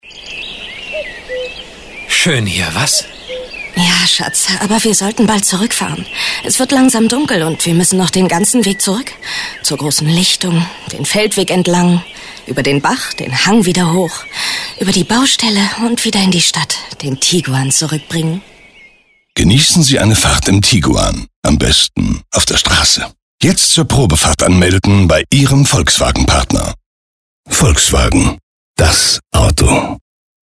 Die deutsche Stimme von Morgan Freeman & Chuck Norris - Jürgen Kluckert
Er synchronisierte über 1.500 Kinofilme und lieh in über 550 Serien vielen Schauspielern seine angenehme markante Stimme.